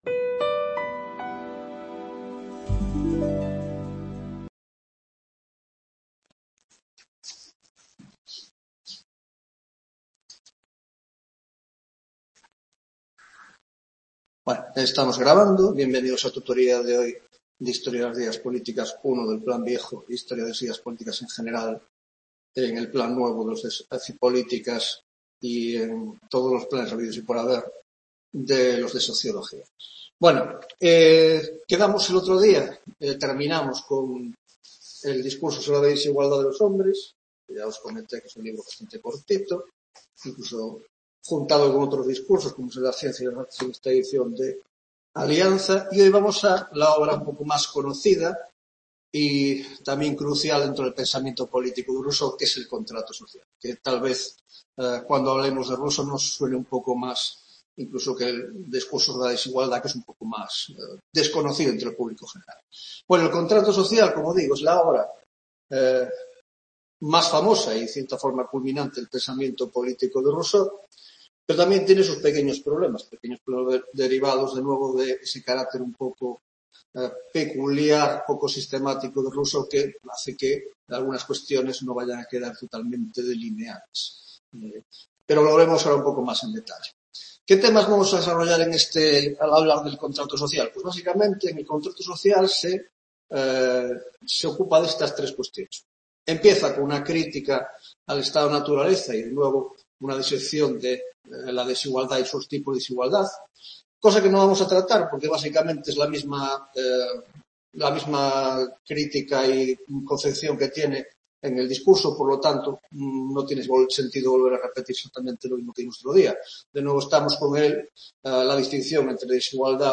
4ª Tutoría de Historia de las Ideas Políticas (Grado de Ciencias Políticas y Grado de Sociología) - Rousseau y la Idea de Democracia (2ª parte): 1) El Contrato Social: 1.1) Diferencias y similitudes entre el Contrato y el Discurso
Room La Coruña (A Coruña)-Campus Noroeste